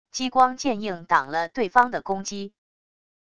激光剑硬挡了对方的攻击wav音频